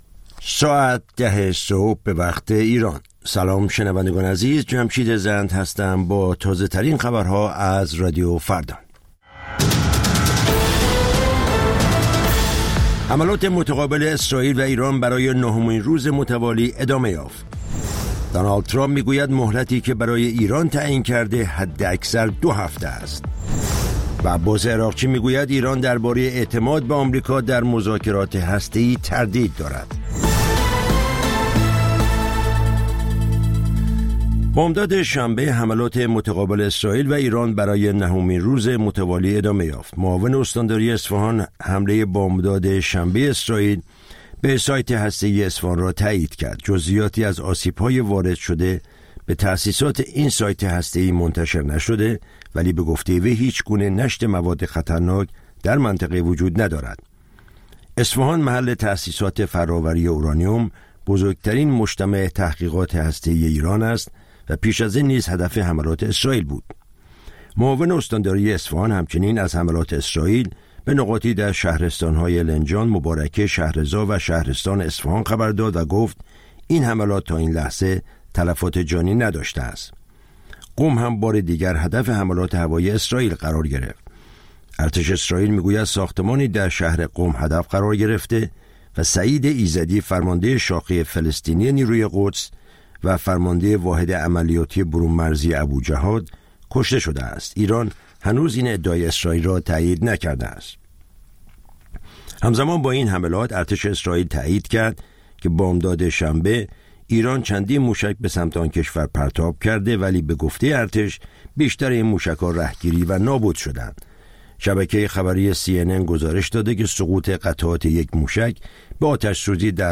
سرخط خبرها ۱۰:۰۰